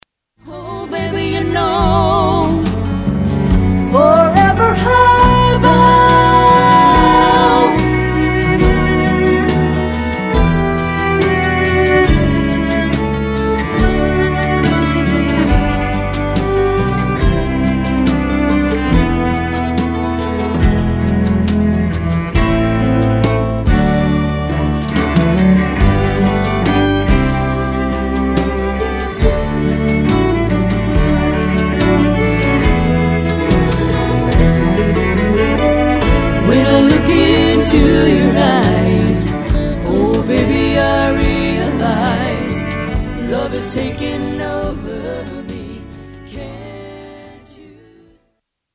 Miking the Cello in the Studio
2.  F-Hole